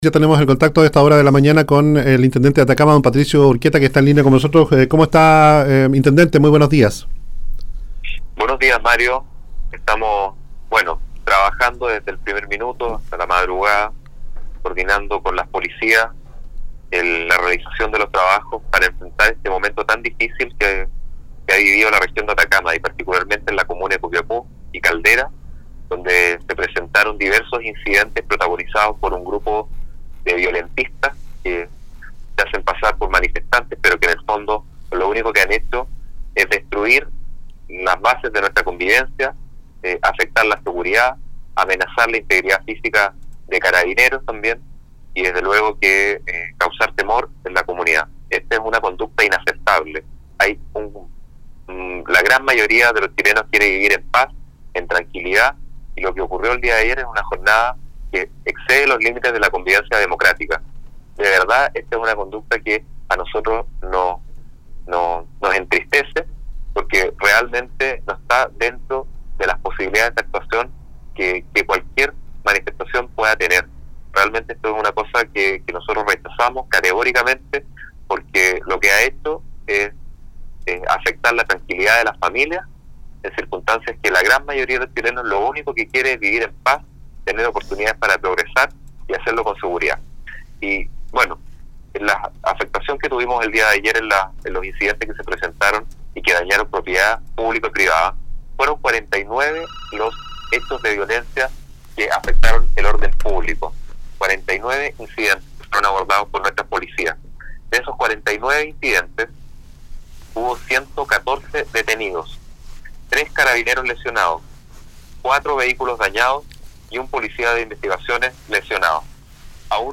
Patricio Urquieta, Intendente de Atacama, ofreció detalles de los trabajos que se están realizando para enfrentar los hechos de violencia que se han registrado en los últimos días en la Región de Atacama, principalmente en las comunas de Copiapó y Caldera donde se han presentado incidentes protagonizados por grupos que lo único que han hecho es destruir lo que se encuentran a su paso.